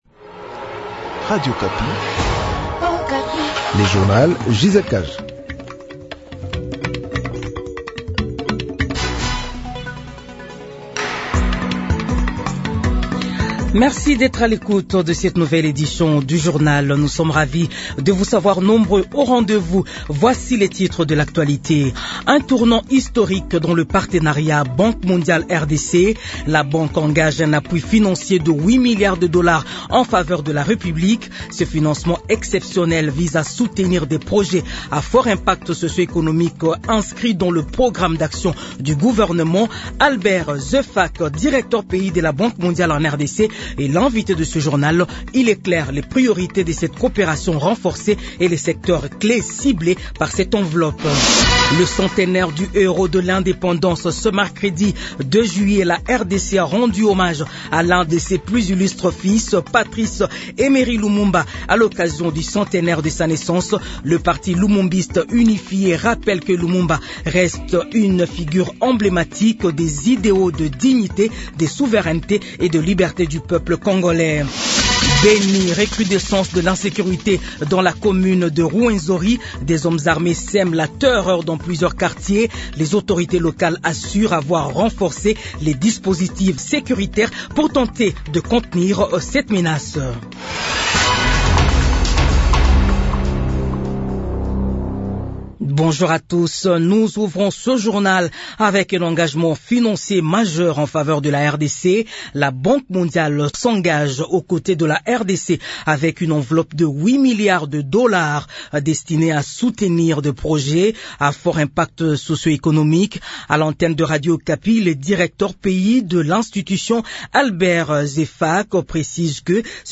Les titres 1. Kinshasa : la Banque mondiale s’engage aux côtés de la RDC avec une enveloppe de 8 milliards de dollars destinée à soutenir des projets à fort impact socio-économique.